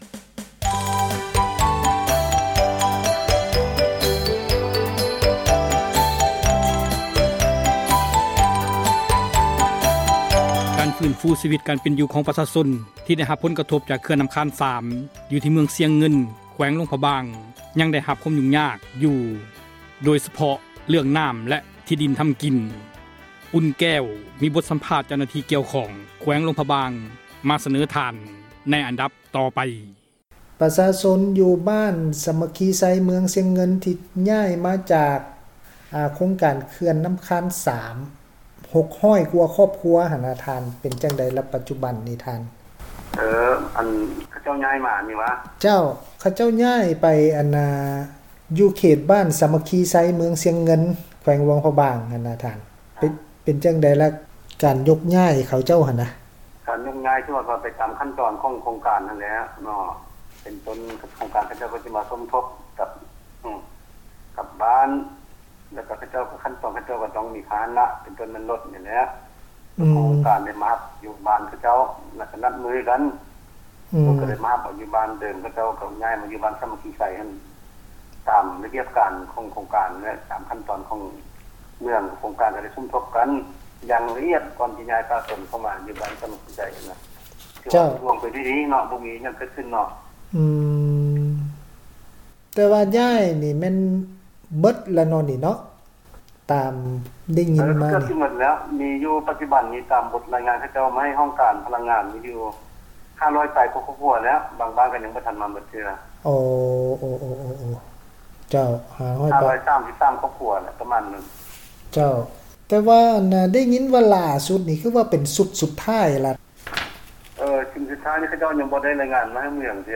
ບົດ ສຳພາດ ເຈົ້າໜ້າທີ່ ກ່ຽວຂ້ອງ ແຂວງ ຫລວງພຣະບາງ